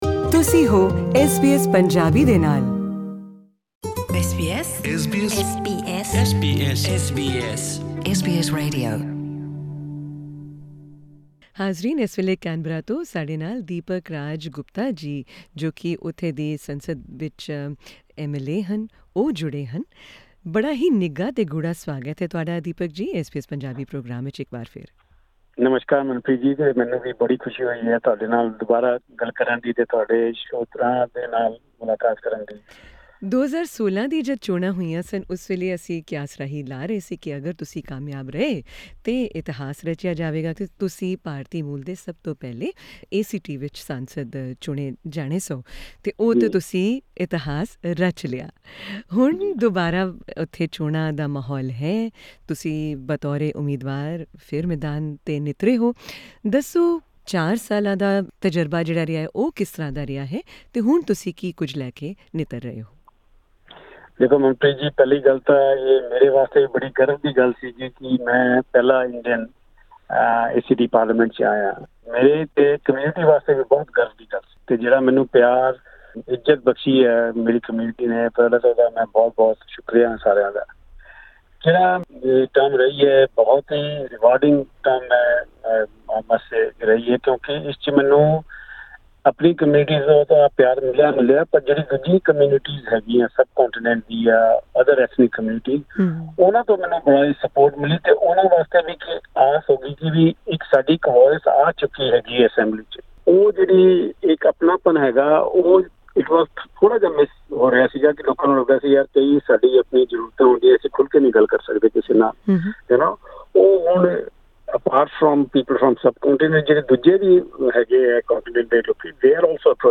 Deepak-Raj Gupta, who made history by becoming the first person of Indian origin to be elected to the ACT parliament, is running for re-election in the Australian Capital Territory's Assembly elections. He spoke to SBS Punjabi about his first term as MLA, his vision for the future, and about campaigning for elections during COVID restrictions.